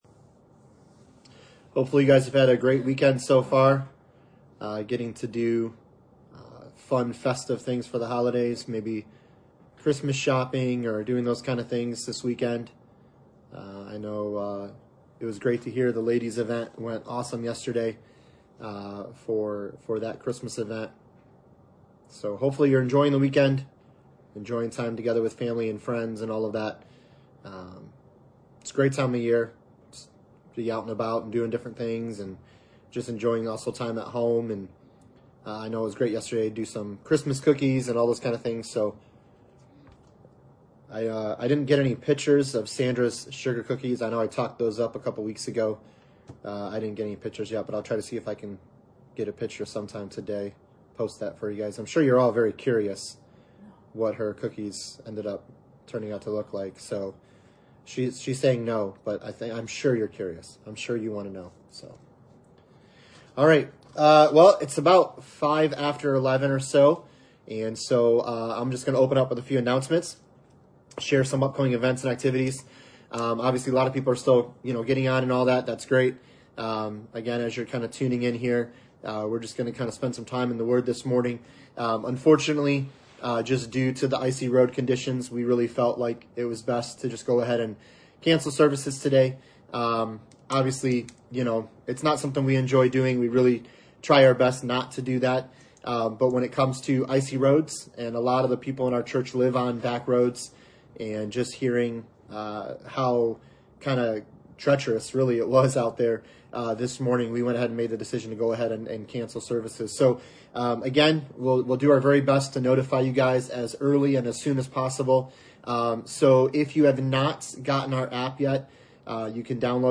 Christmas Stories Passage: Luke 2:8-20 Service Type: Sunday Morning « LIFT Christmas 2024 It Is A Story of Grace for All